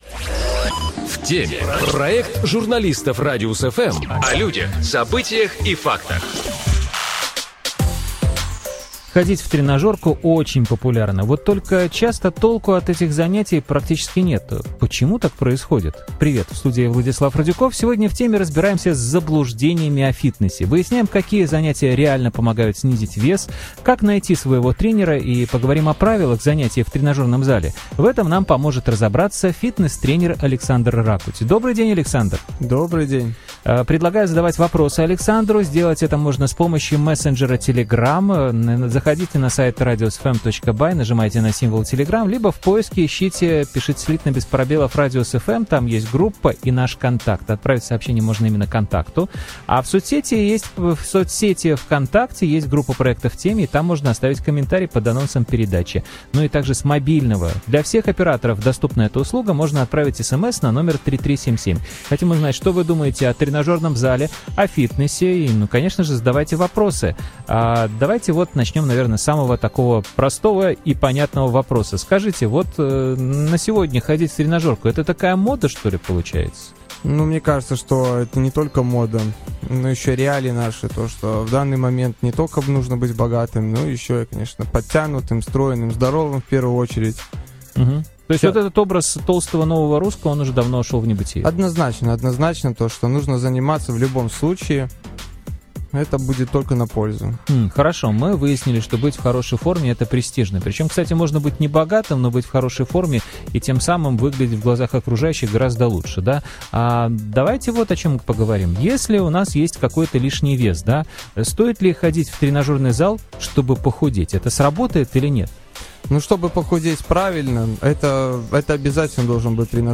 Выясняем, какие занятия реально помогают снизить вес, как найти «своего» тренера и поговорим о правилах занятий в тренажёрном зале. У нас в гостях - фитнес-тренер